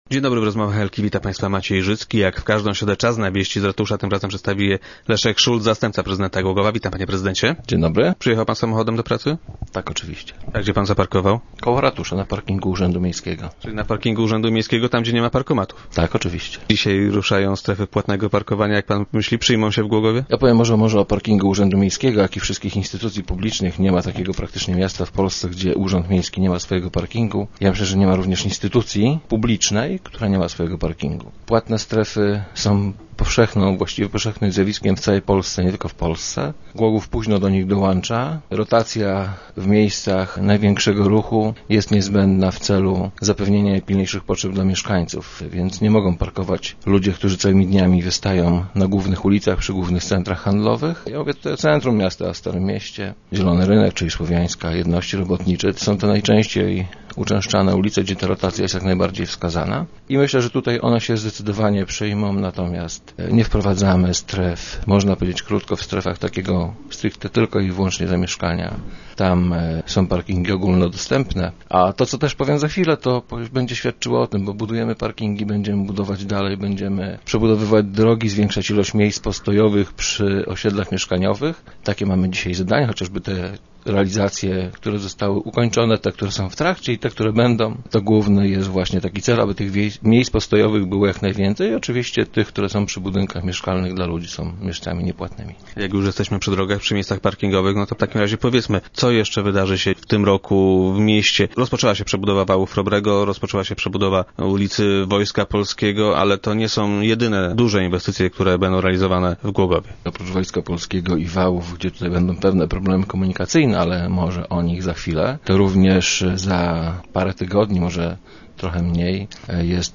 Jak powiedział Leszek Szulc, zastępca prezydenta i gość dzisiejszych Rozmów Elki, władze miasta postarają się, żeby te utrudnienia nie były bardzo uciążliwe.